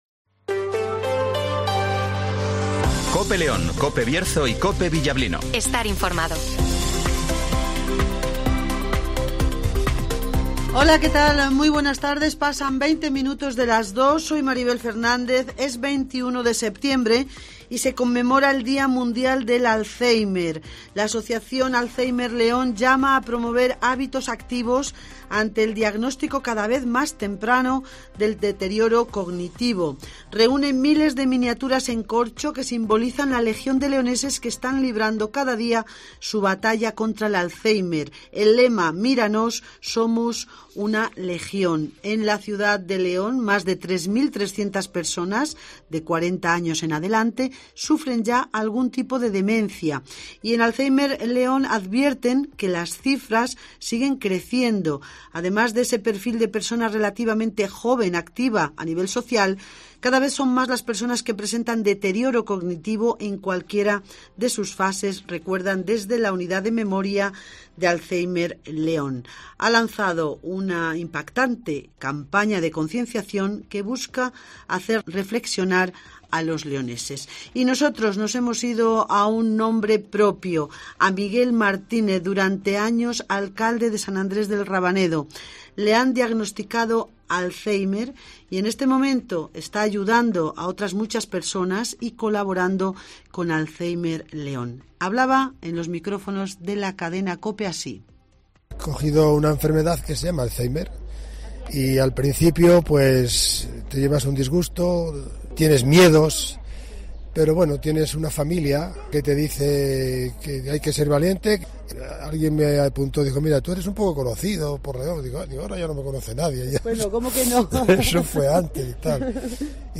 Informativo Mediodia